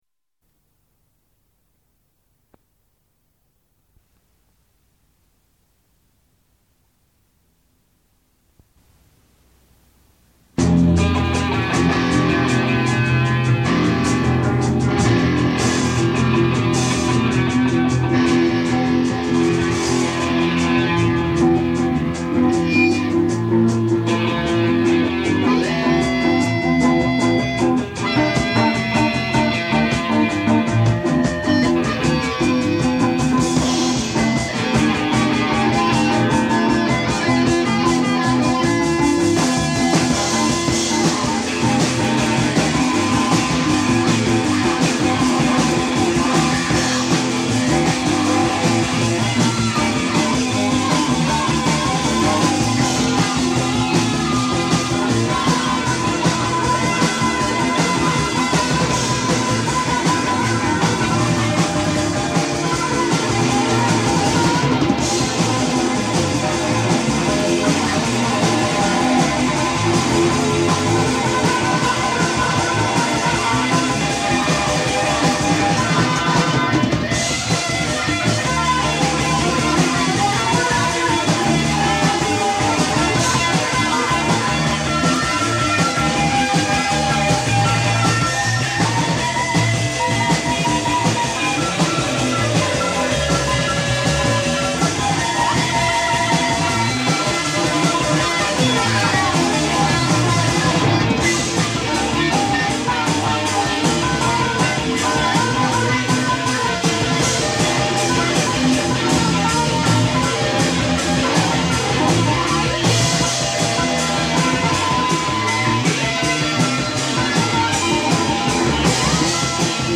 drums
guitar
bass